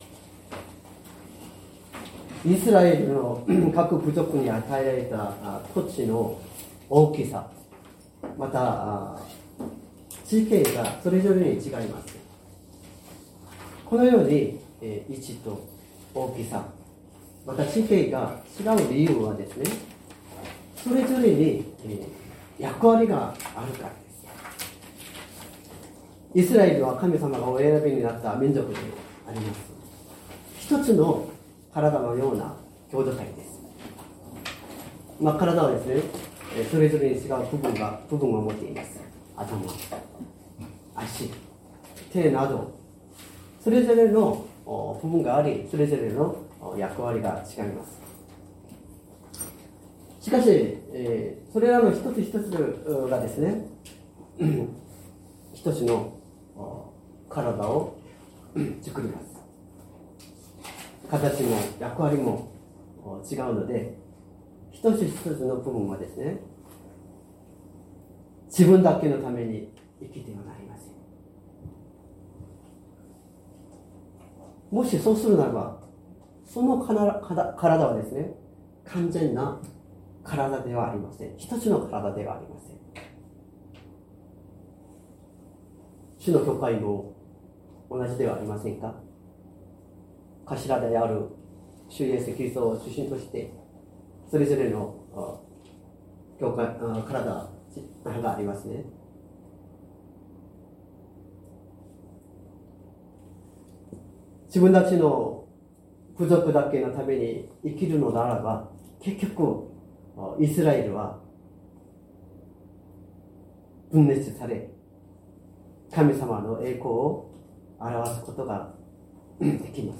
説教アーカイブ 2025年03月02日朝の礼拝「神様のお働き」
音声ファイル 礼拝説教を録音した音声ファイルを公開しています。